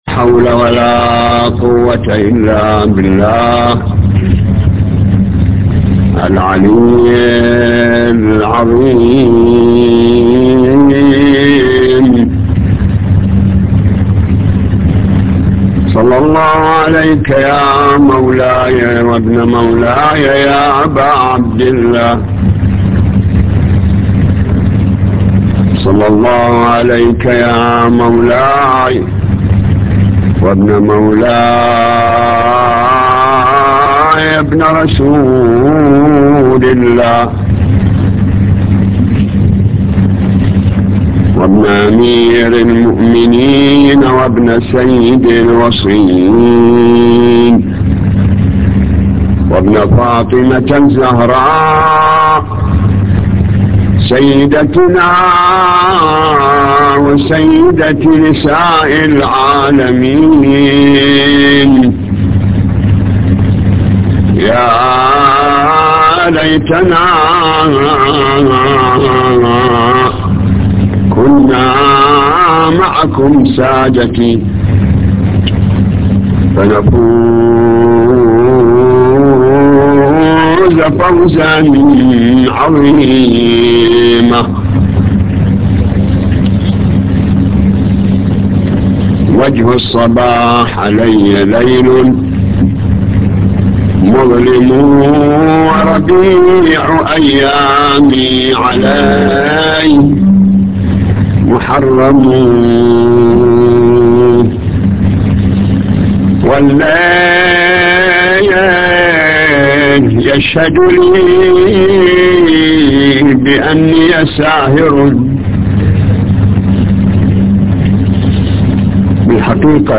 نواعي حسينية 4